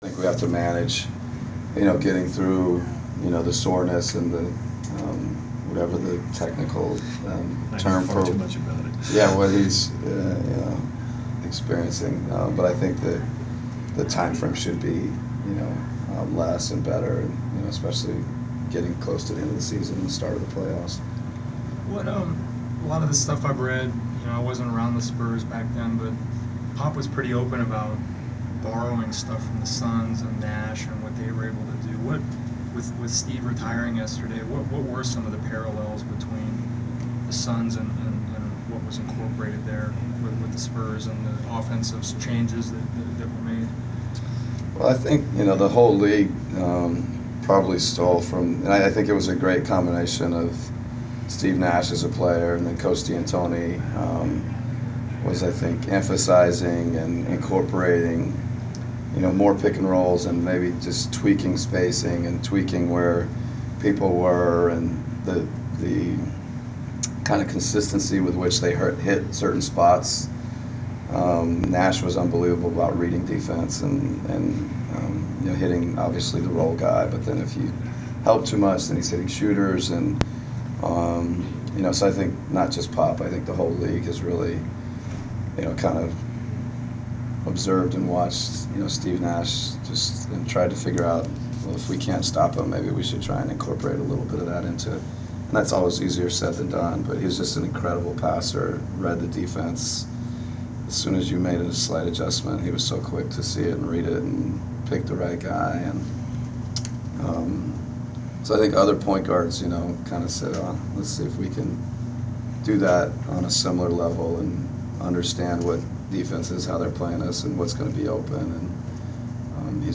Inside the Inquirer: Pregame presser with Atlanta Hawks’ head coach Mike Budenholzer (3.22.15)
We attended the pregame presser of Atlanta Hawks’ head coach Mike Budenholzer before his team’s home contest against the San Antonio Spurs on Mar. 22. Topics included Kyle Korver’s return from injury and team’s approach to contests at this stage of the season.